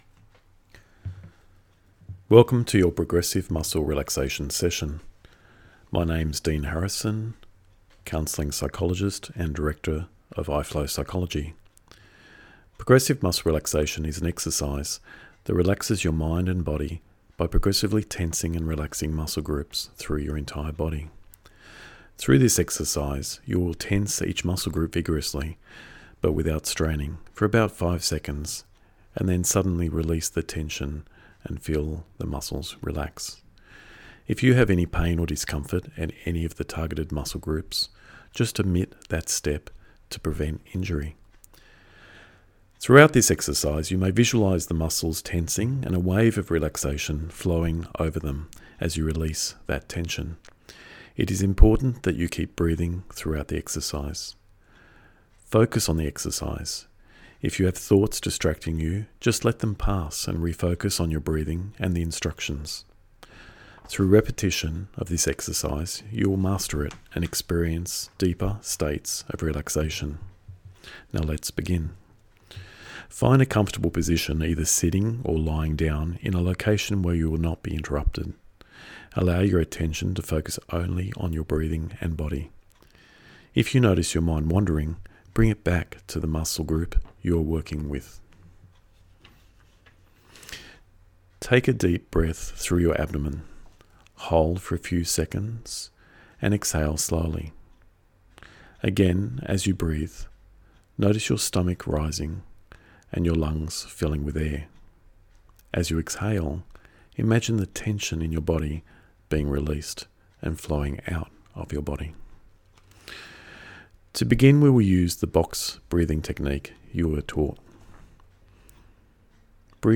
Progressive Muscle Relaxation Audio | Guided Relaxation Sydney